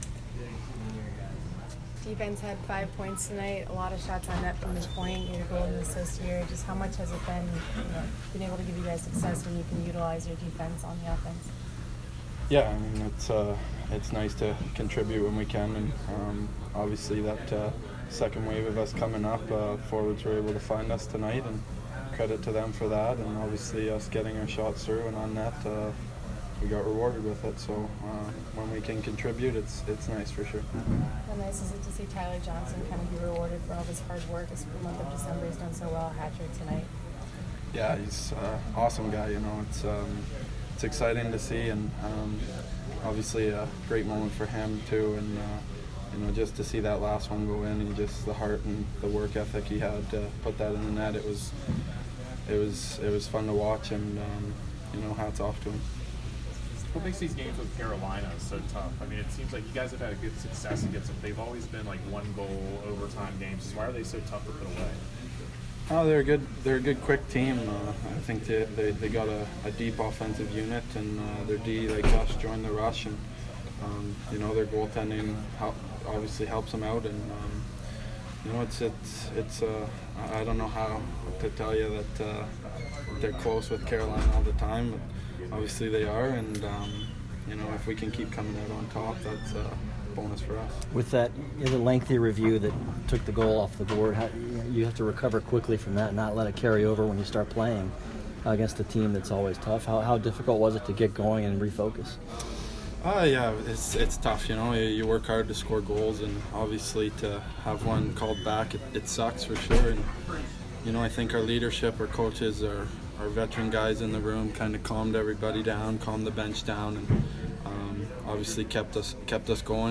Jake Dotchin Post-Game 1/9